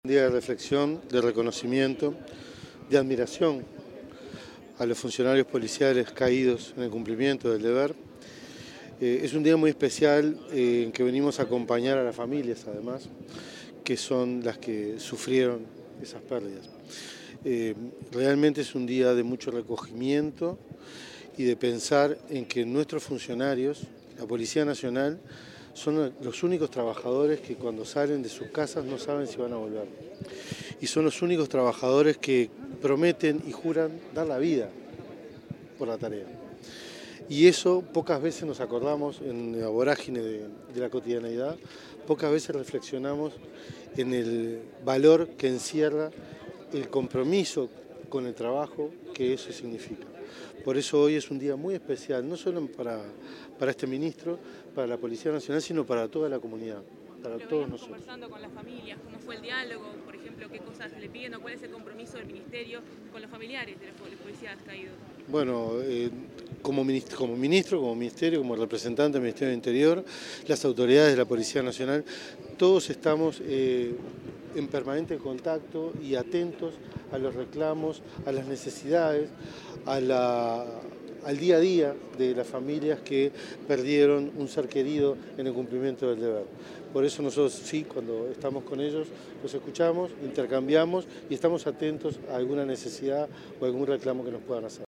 Declaraciones del ministro del Interior, Carlos Negro
Declaraciones del ministro del Interior, Carlos Negro 20/11/2025 Compartir Facebook X Copiar enlace WhatsApp LinkedIn Tras la conmemoración del Día del Policía Caído en Cumplimiento del Deber, este jueves 20 en la plaza de la Policía, el ministro del Interior, Carlos Negro, diálogo con los medios de prensa.